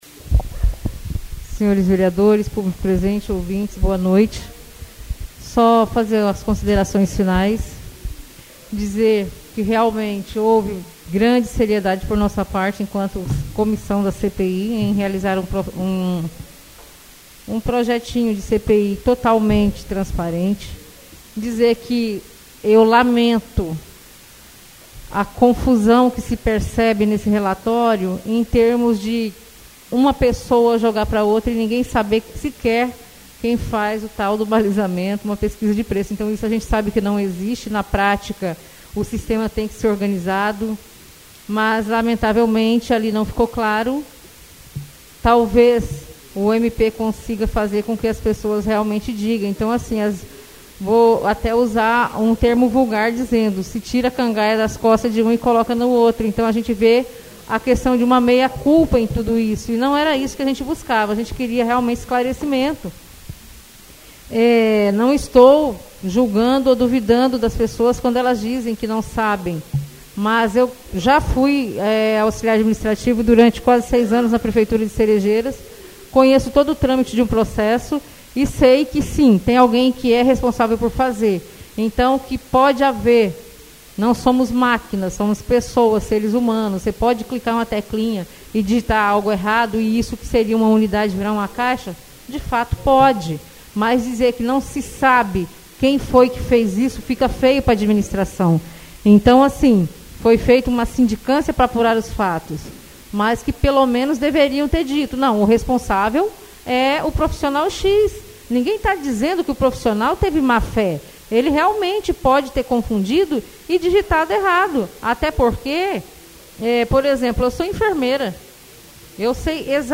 Oradores das Explicações Pessoais (28ª Ordinária da 3ª Sessão Legislativa da 6ª Legislatura)